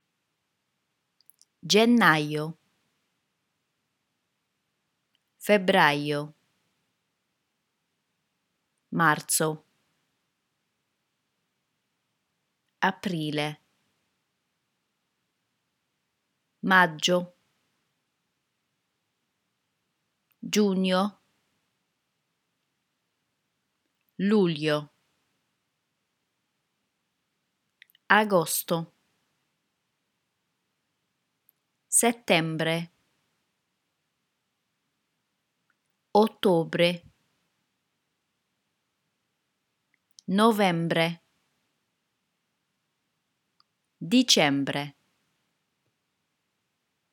Let's go through the pronunciation of the months. Repeat after each pronunciation you listen to in the clip.